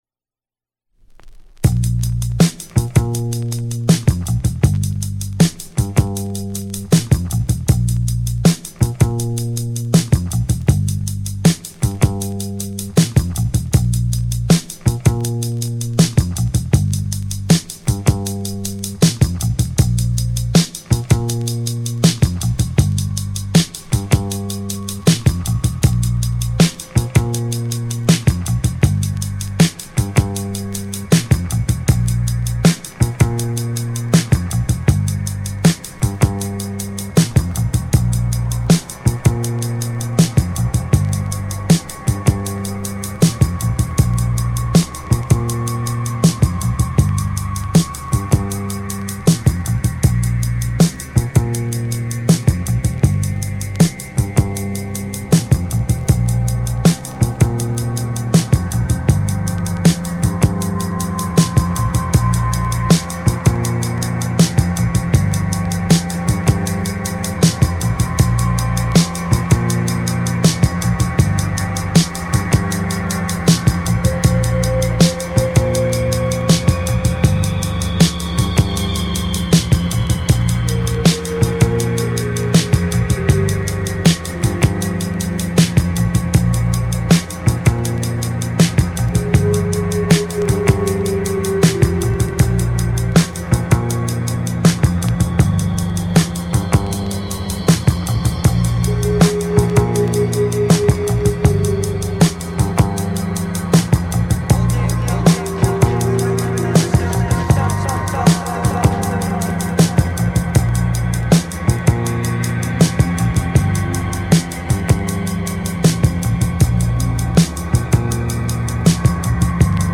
Alt Disco / Boogie